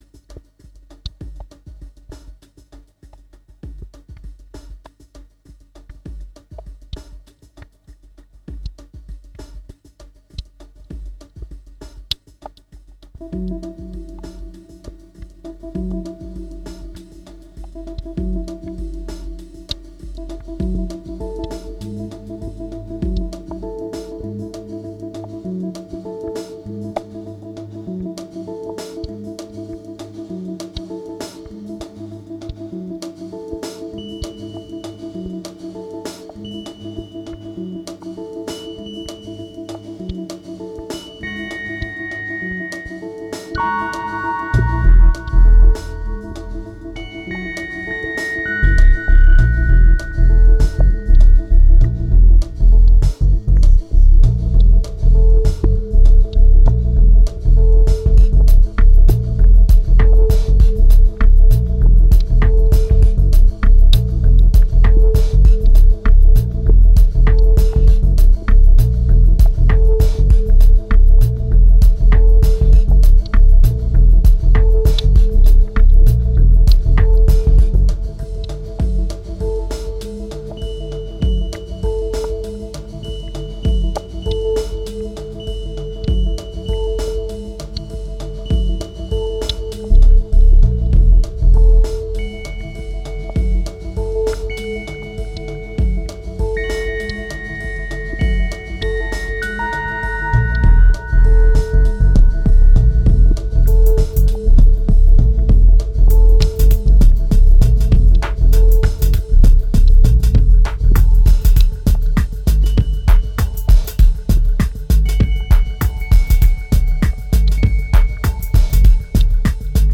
2018📈 - -6%🤔 - 99BPM🔊 - 2010-11-19📅 - -502🌟